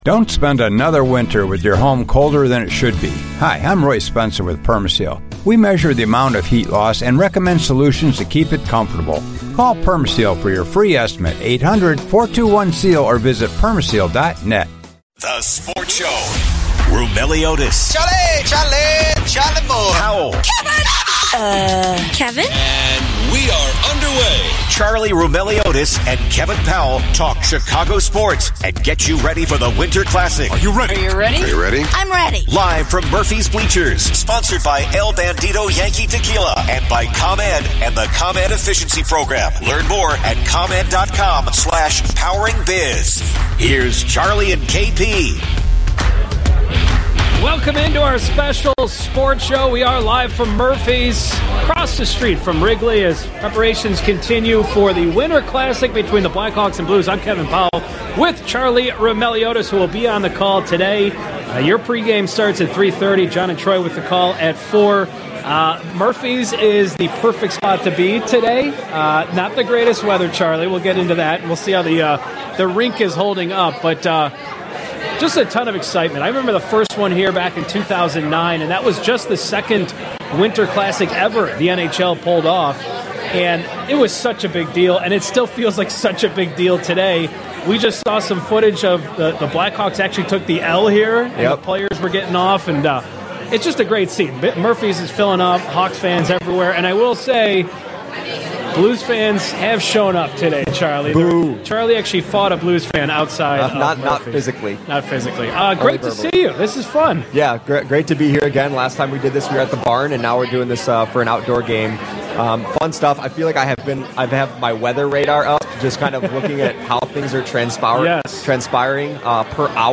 broadcast live from Murphy’s Bleachers across from Wrigley Field in Chicago ahead of the Blackhawks Winter Classic matchup against the St. Louis Blues.